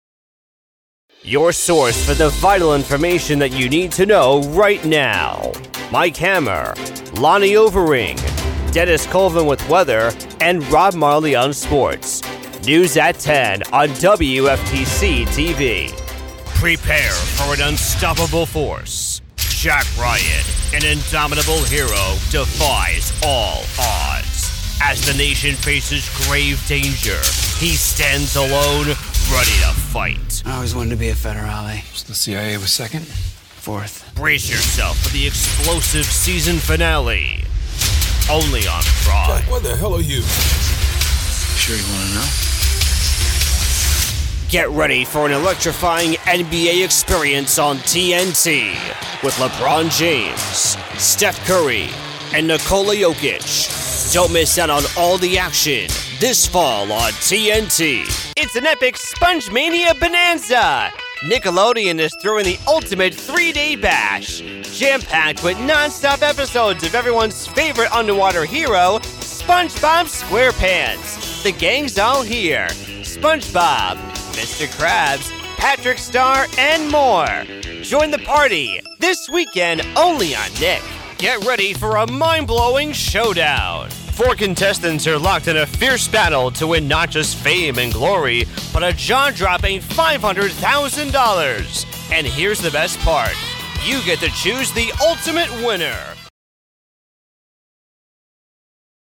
Focusrite Scarlett 2i2 3rd Gen USB Audio Interface
AKG P220 Large-diaphragm Condenser Microphone
Promo